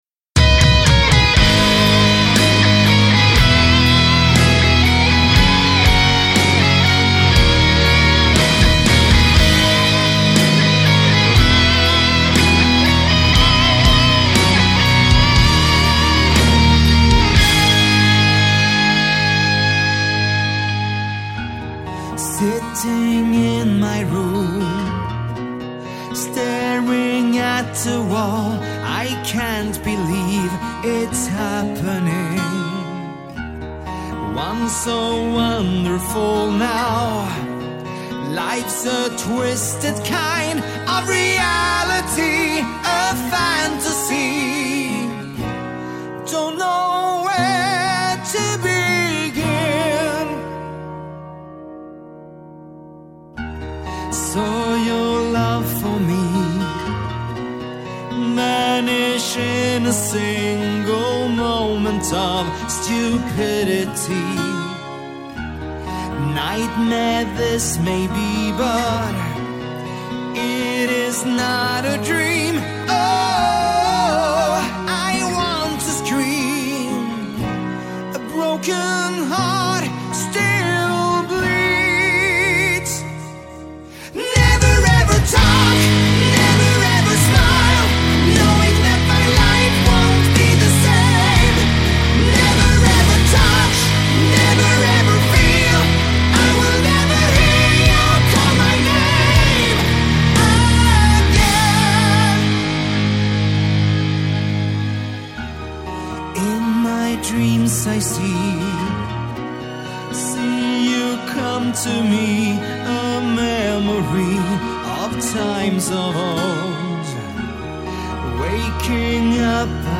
Жанр: epicmetal